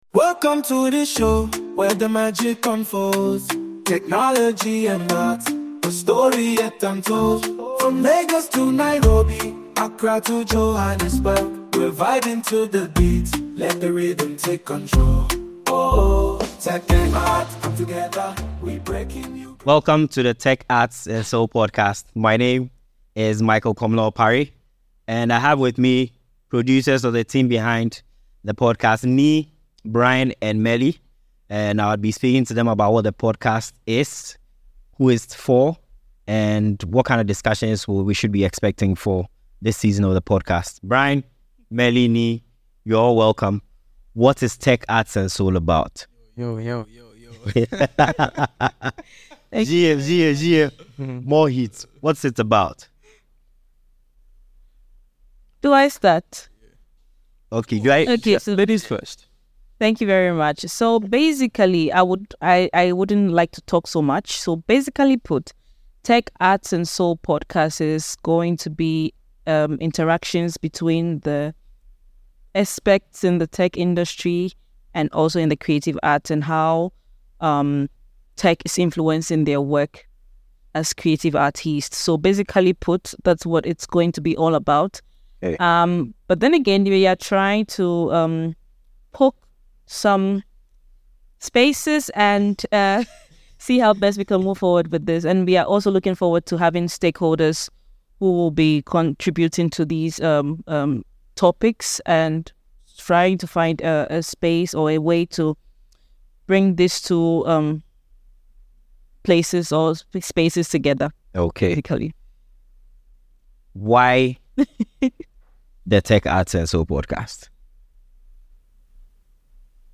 The podcast explores these interplays through authentic, unscripted conversations with artists, technologists, and thought leaders who challenge our view of the world.